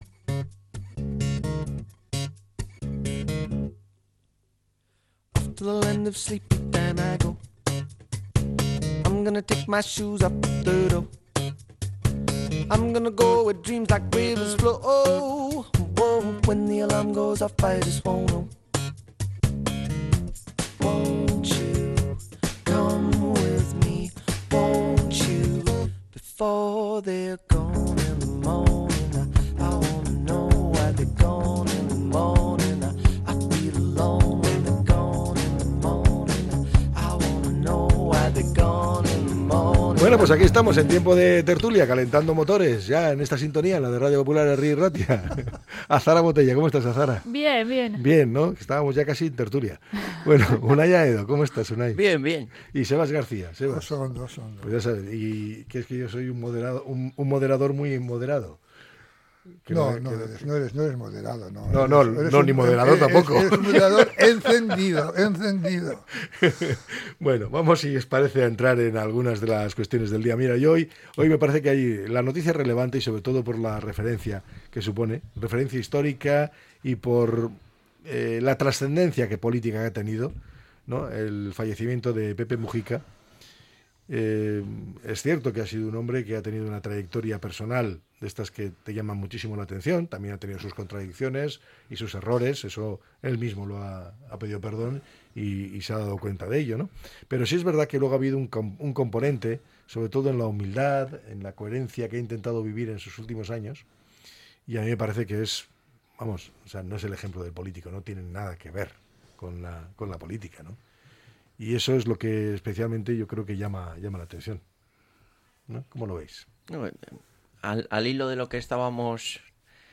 La tertulia 14-05-25.